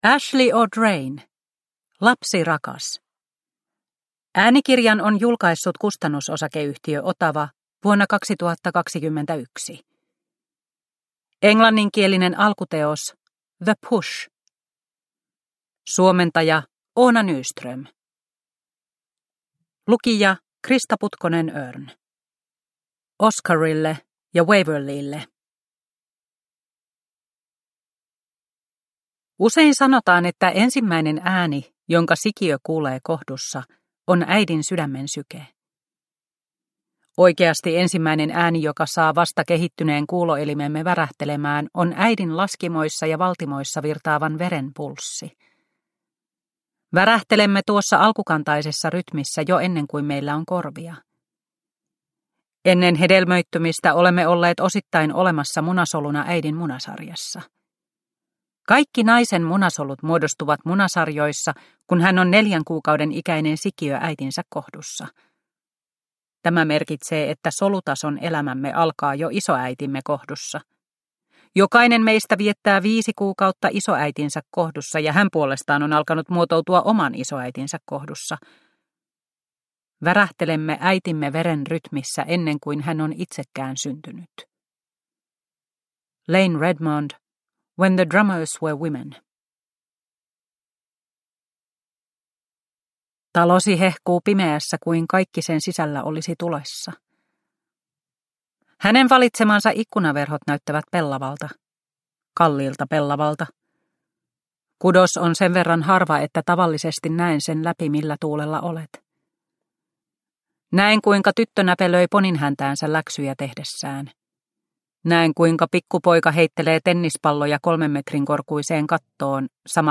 Lapsi rakas – Ljudbok – Laddas ner